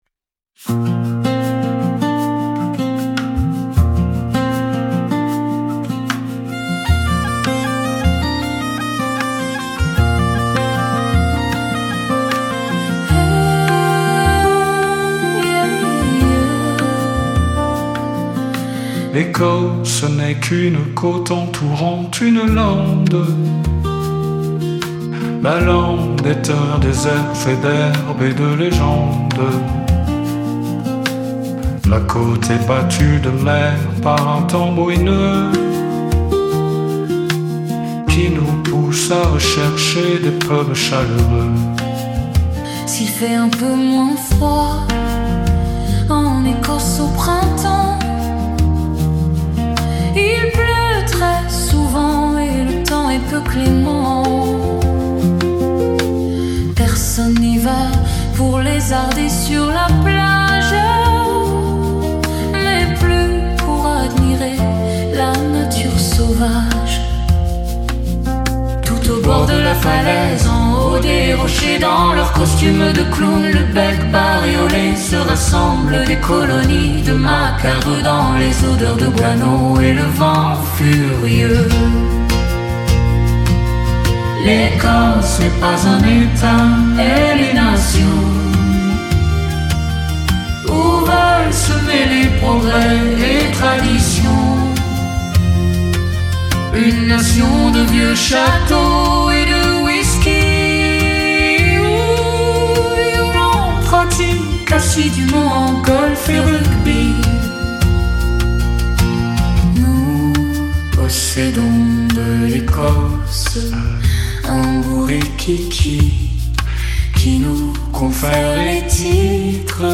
Mais dans l’enregistrement, c’st bien une cornemuse (sans la bourdon car ils saturaient totalement la chanson)